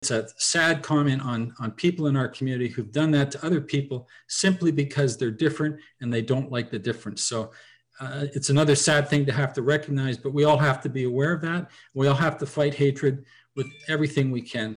Sunday’s horrific vehicle attack in London, Ontario that killed four people was a topic of discussion Monday night at a meeting of Quinte West council.
Councillor Terry Cassidy.